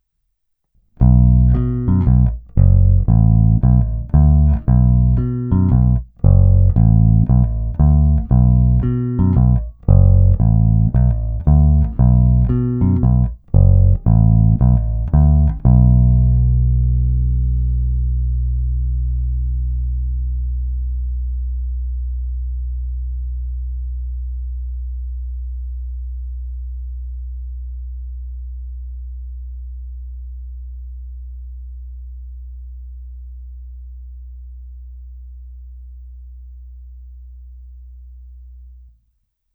Hlazenky base sluší, zvuk je pěkně tučný, pevný, má ty správné středy tmelící kapelní zvuk.
Není-li uvedeno jinak, následující nahrávky jsou provedeny rovnou do zvukové karty, jen normalizovány, jinak ponechány bez úprav.
Snímač u krku